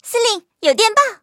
M2中坦查看战绩语音.OGG